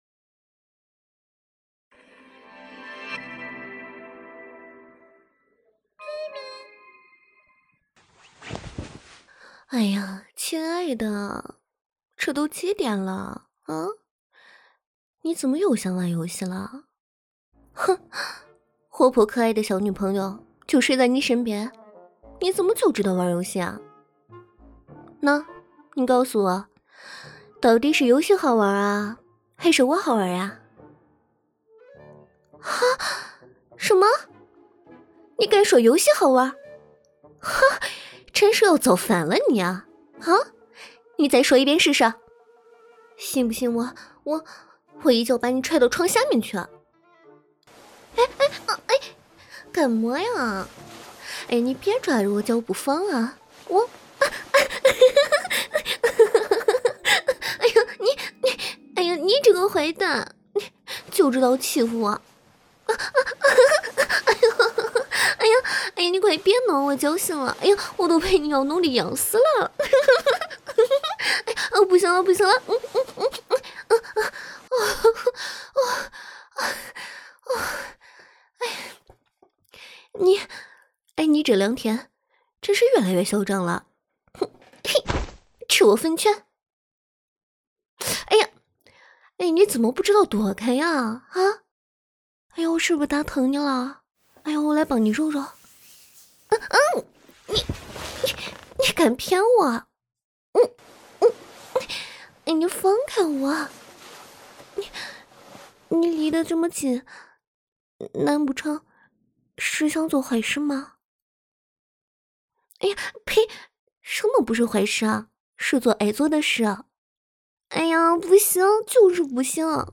【山东方言】
平淡/轻松 女性视角 少女